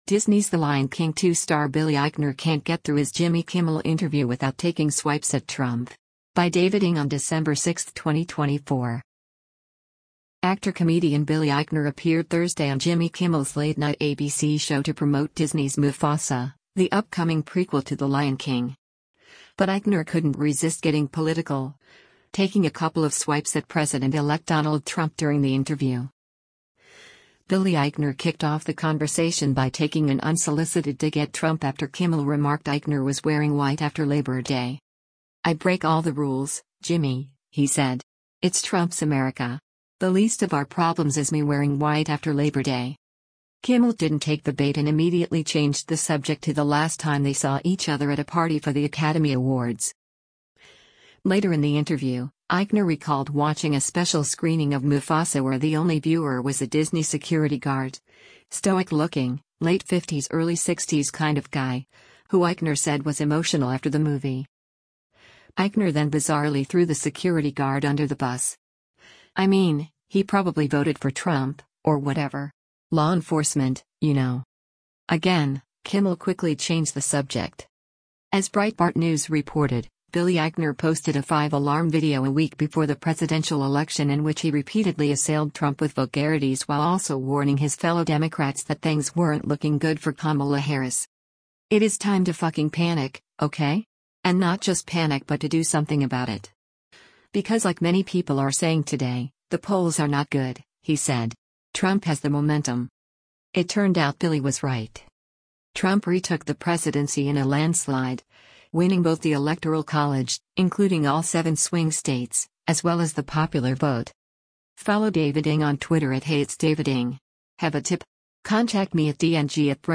Actor-comedian Billy Eichner appeared Thursday on Jimmy Kimmel’s late-night ABC show to promote Disney’s Mufasa, the upcoming prequel to The Lion King.